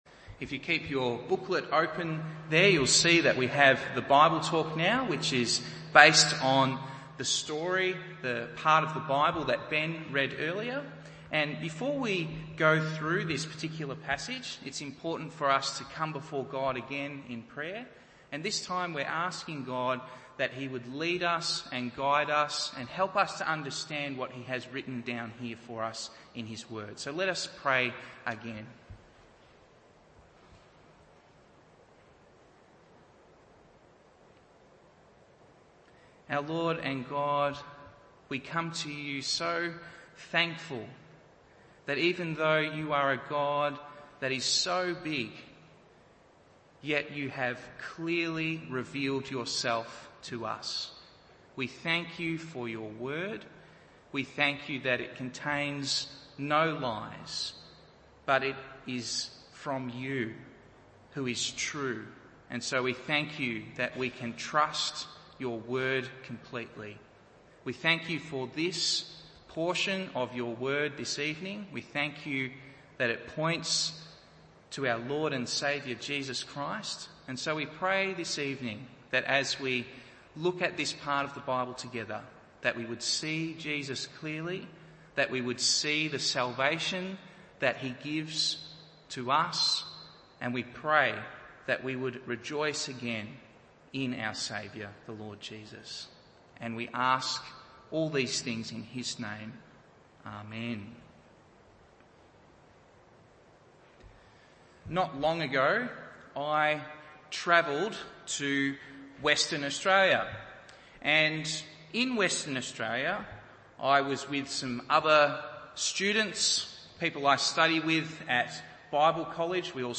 Easy English Church - Meeting Jesus: Jesus Sets a Woman Free - South Yarra Presbyterian Church
Evening Service Meeting Jesus: Jesus Sets a Woman Free Luke 13:10-17 1. Jesus bring freedom (verse 10 to verse 13) 2. God’s rest day proclaims freedom (verse 14 to verse 16) 3. Are you free (verse 17)?